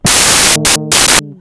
They were recorded with my custom Kaminski 4-string fretless.